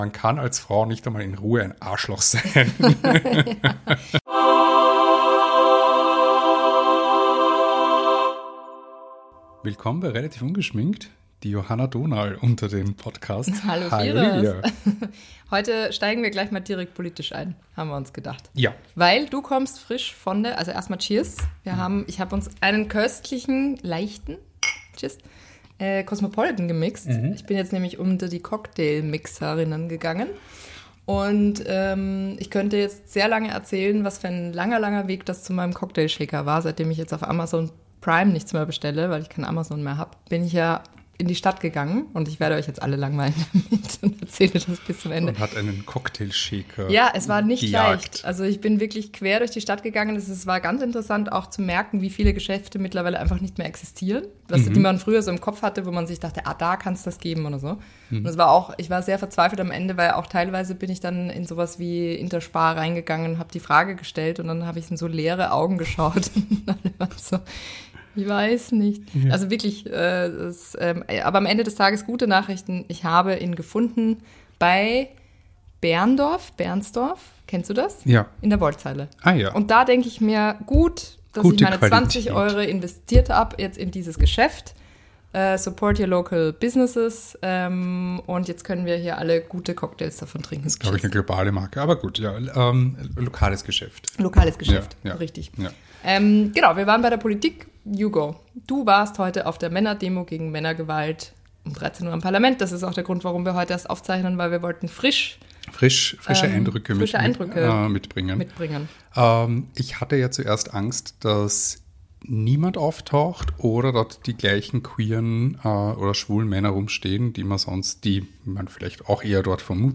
Intro: Sound Effect
Outro: Sound Effect